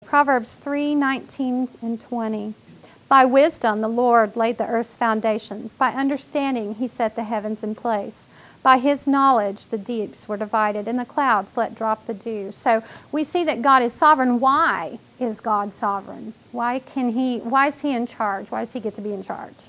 This workshop will examine the "land" part of the earth, called the lithosphere, and Biblical principles that teach of God's continuing sovereignty over it.